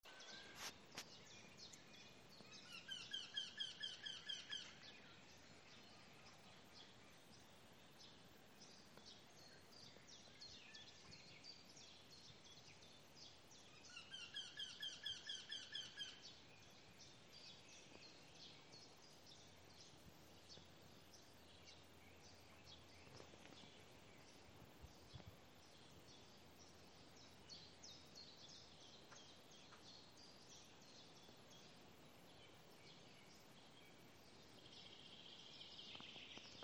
Птицы -> Дятловые ->
вертишейка, Jynx torquilla
СтатусПоёт
divi putni